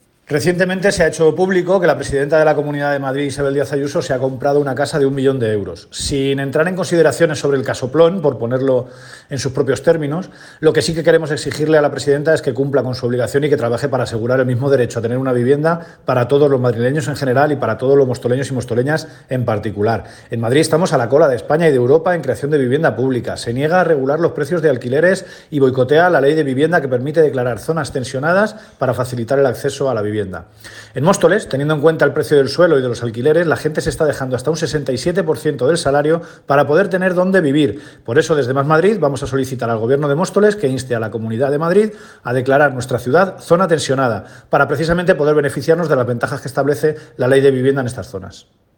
declaraciones-emilio-delgado-vivienda.mp3